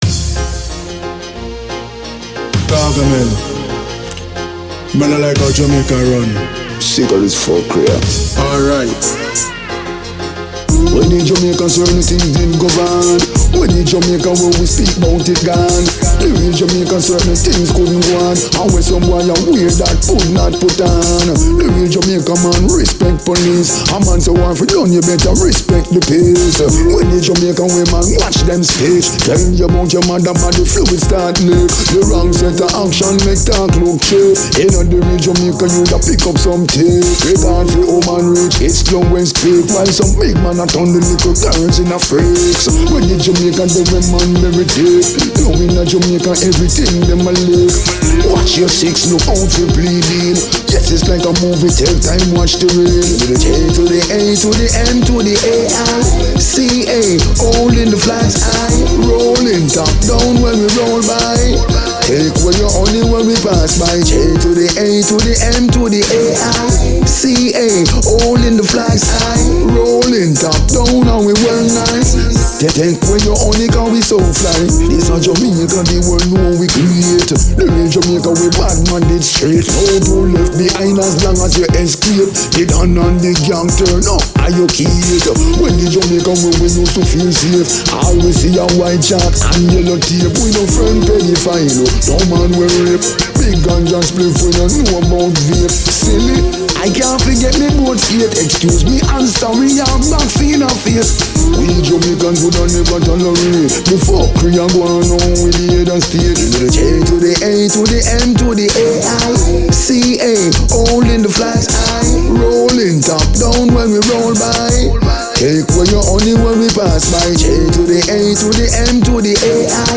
a Jamaican dancehall performer and record producer